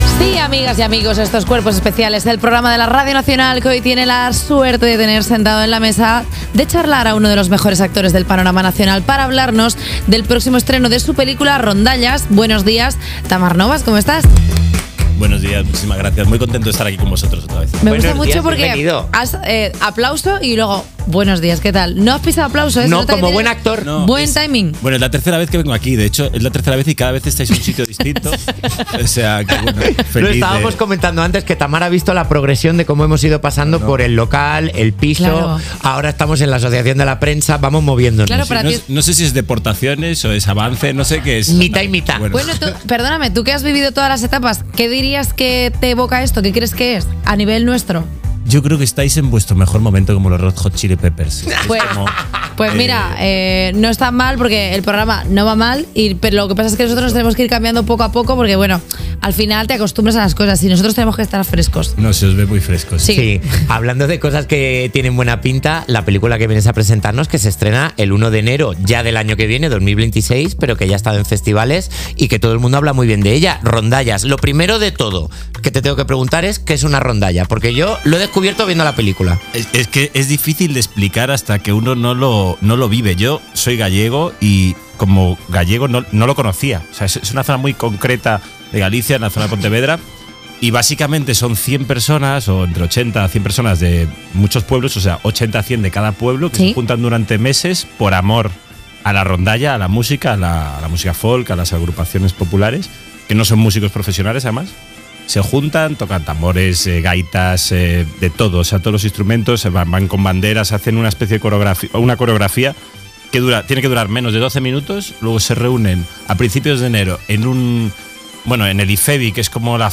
La entrevista de Tamar Novas en 'Cuerpos especiales'